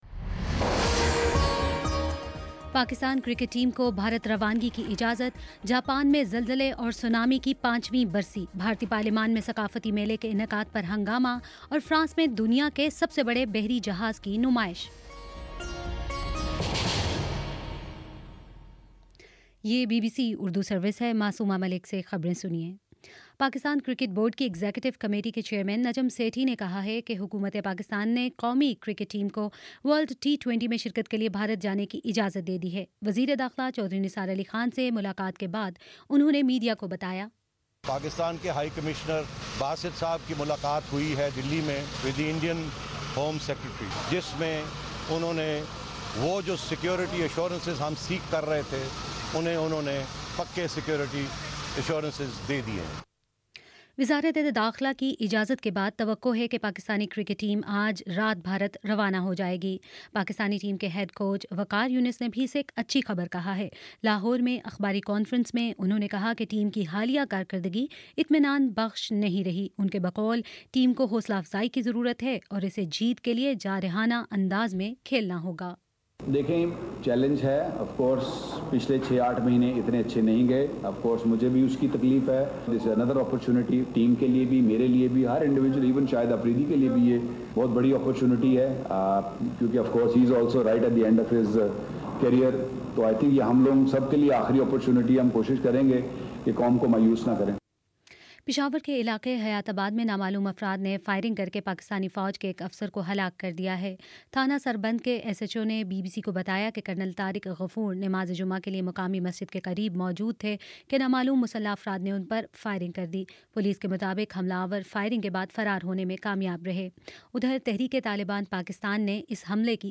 مارچ 11 : شام سات بجے کا نیوز بُلیٹن